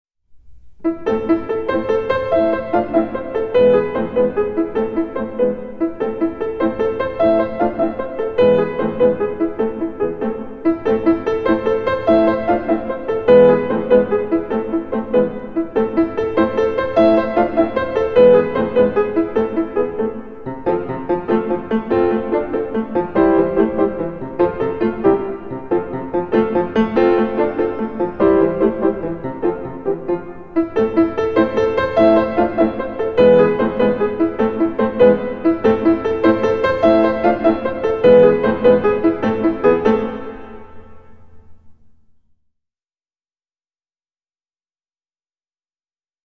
фортепианная версия